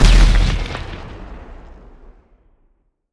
goodcircle/IdleRPG2024 - Assets/_8Sound/Grenade Sound FX/Grenade/skill18_explosion.wav at c111c38667e5bd2f6848d1ef4c15c01eaa5fd40c - IdleRPG2024 - GoodCircle
skill18_explosion.wav